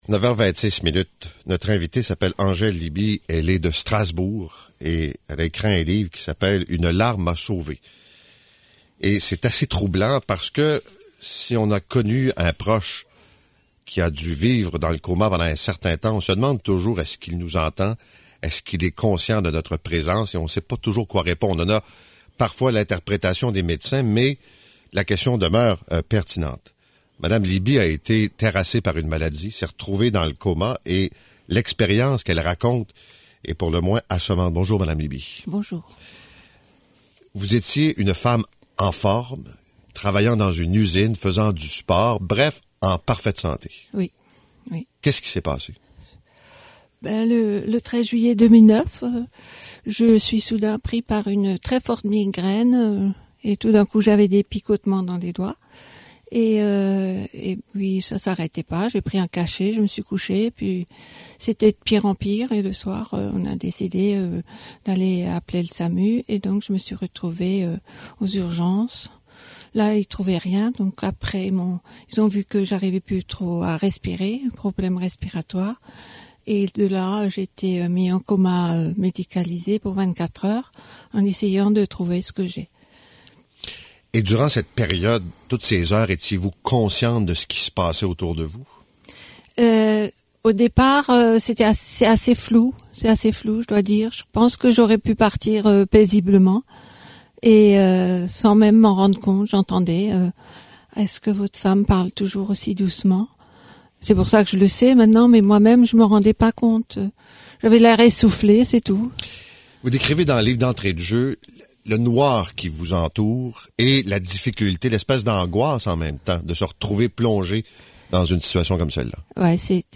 Entrevue audio :